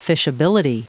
Prononciation du mot fishability en anglais (fichier audio)
Prononciation du mot : fishability